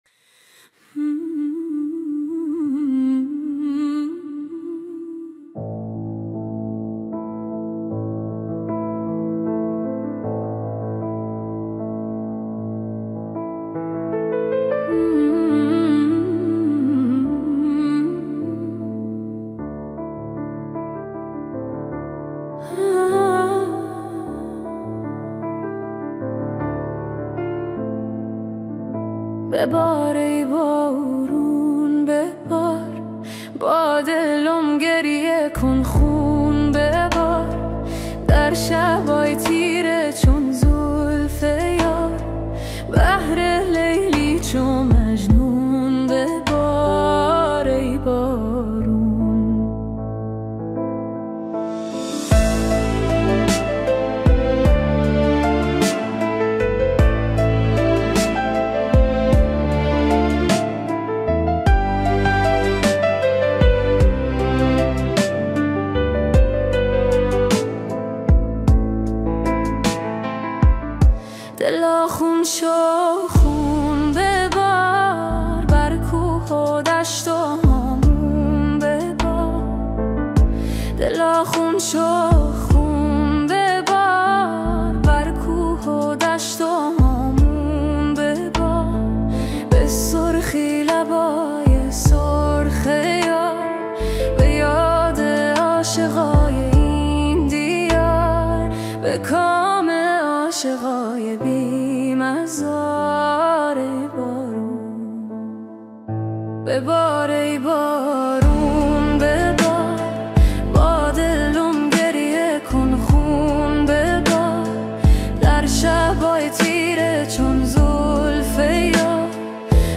با سبک بالاد هنری سینمایی اجرا شده است
Atmospheric Emotional Ballad Remix